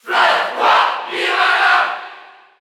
Category: Crowd cheers (SSBU) You cannot overwrite this file.
Piranha_Plant_Cheer_French_NTSC_SSBU.ogg